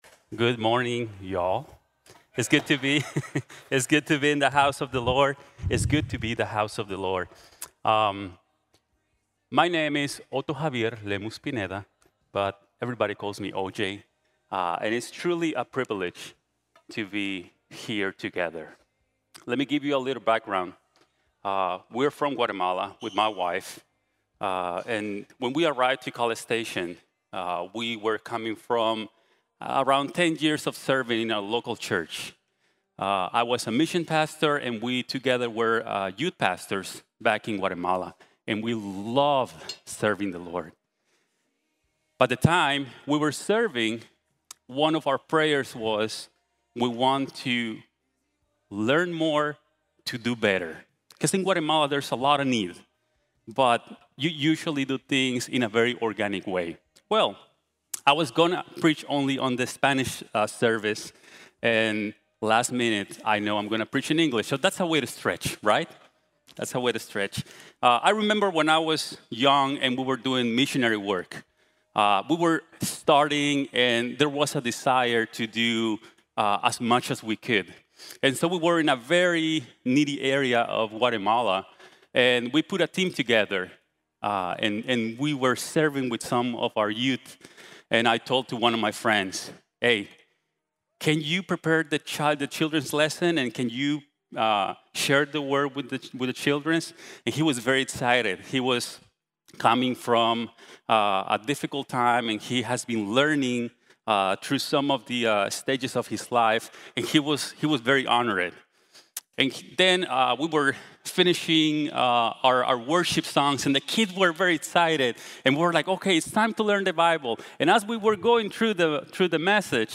Bibliología | Sermón | Iglesia Bíblica de la Gracia